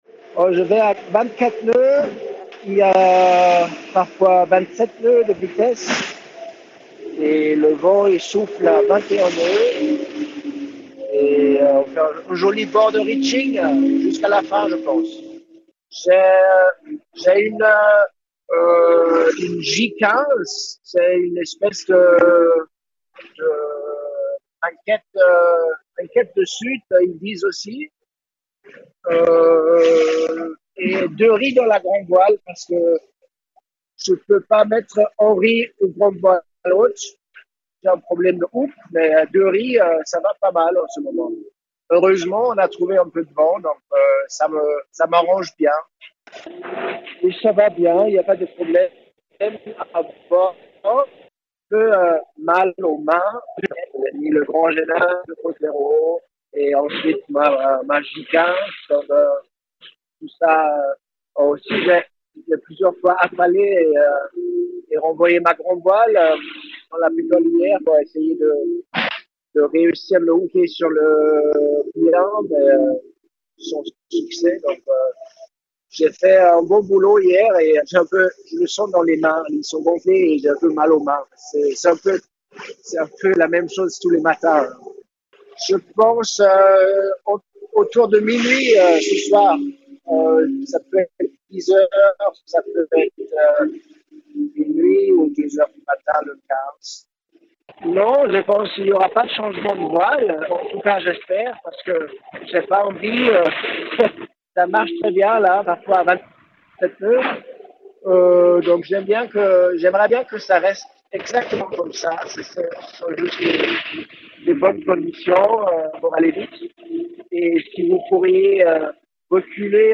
Joints à 5h ce mardi 14 juillet, les skippers nous racontent les conditions dans lesquelles ils naviguent sur ce tout dernier tronçon avant la ligne d'arrivée devant Les Sables d'Olonne...